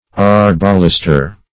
Meaning of arbalester. arbalester synonyms, pronunciation, spelling and more from Free Dictionary.